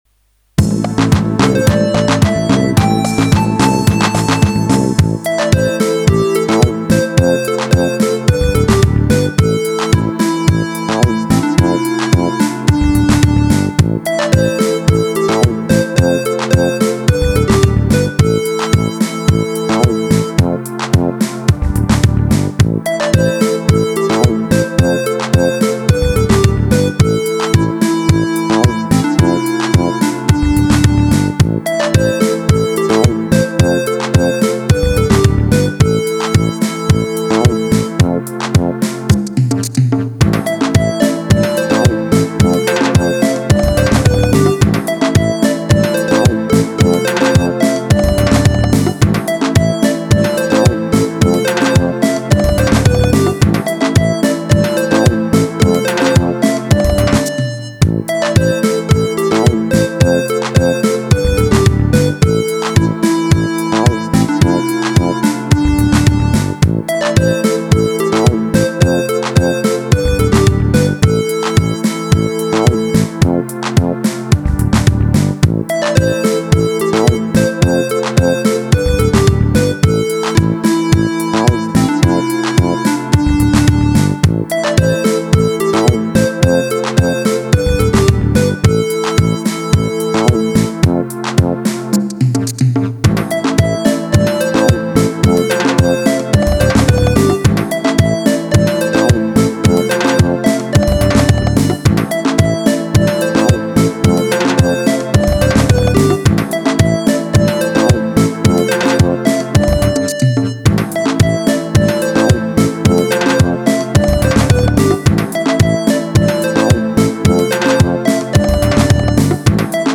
Latin
Een ritme met een frisse, vrolijke uitstraling dat meteen lekker in het gehoor ligt. Alles viel mooi op zijn plaats, waardoor het geheel vlot en natuurlijk aanvoelt.
Ik heb het opnieuw bewust eenvoudig gehouden, maar net daarin zit de kracht: simpel, strak en toch met karakter.
Het tempo bedraagt 109 BPM.